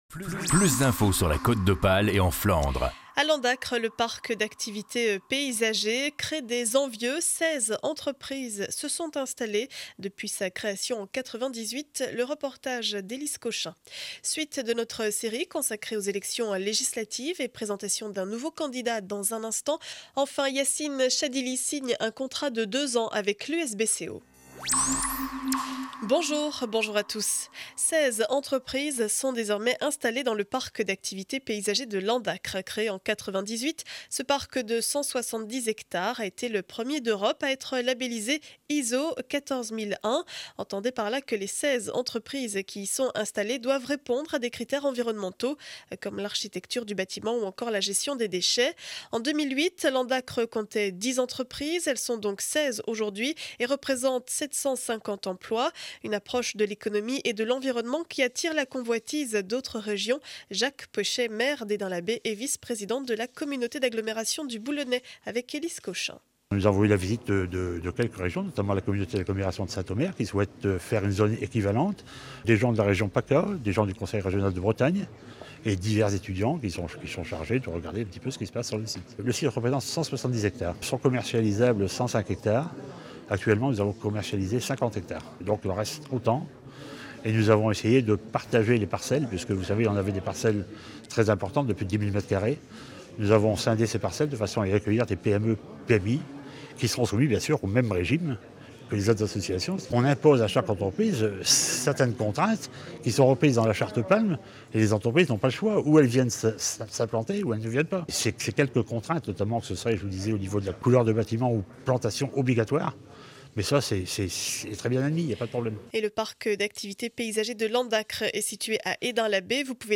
Journal du mercredi 23 mai 2012 7 heures 30 édition du Boulonnais